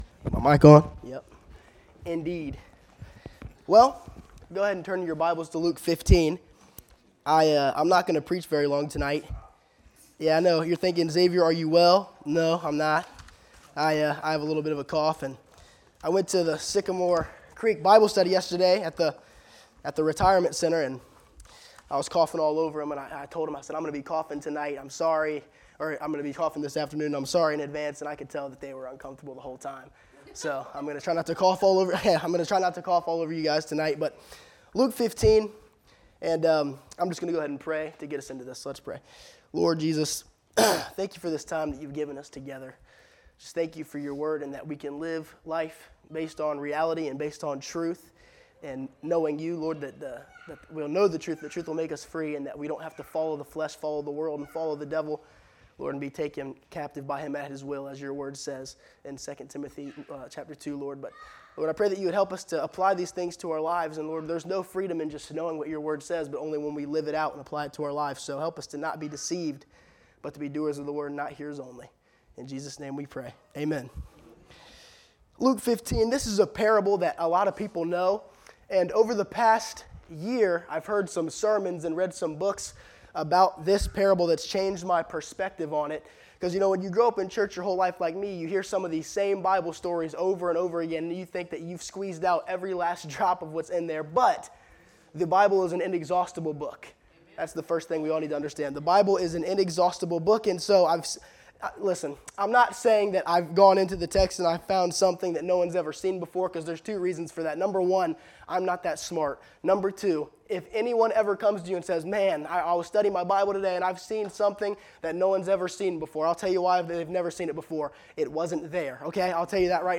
Wednesday Evening Bible Study